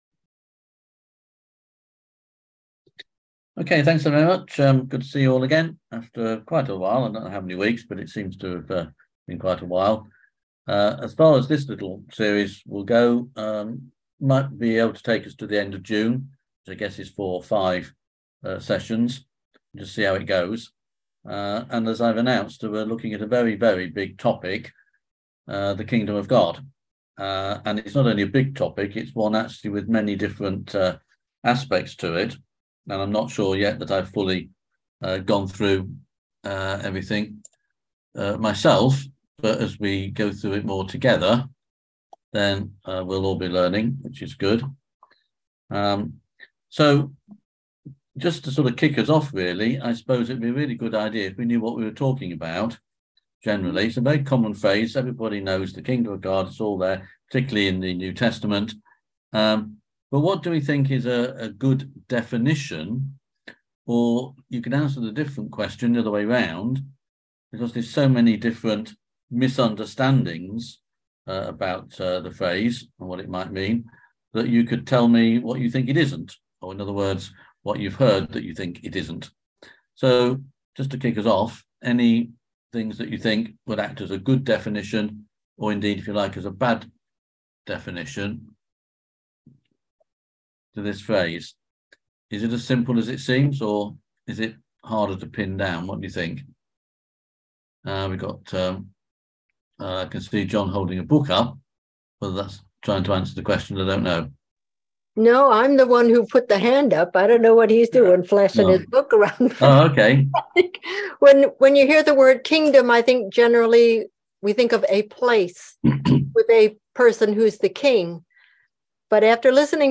On May 25th at 7pm – 8:30pm on ZOOM